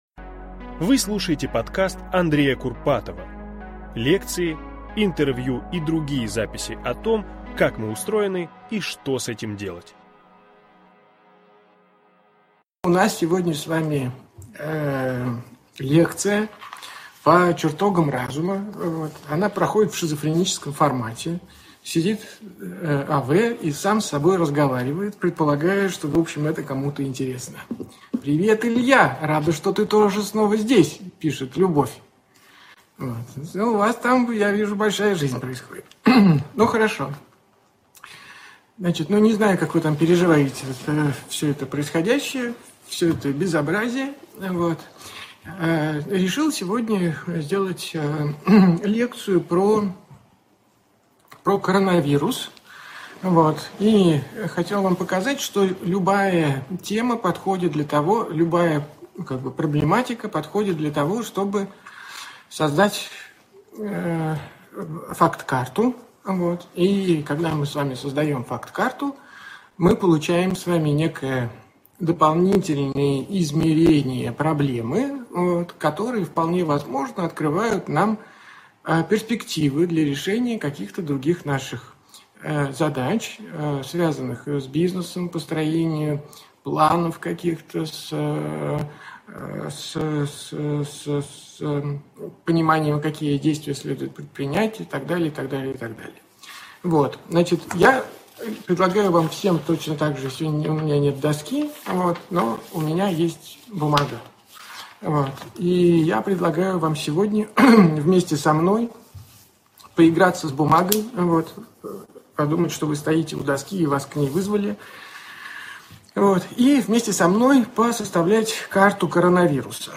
Aудиокнига Что нас ждёт после карантина? Автор Андрей Курпатов Читает аудиокнигу Андрей Курпатов.